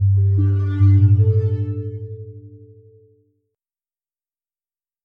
Звуки включения устройств
Электронный сигнал включения портативного устройства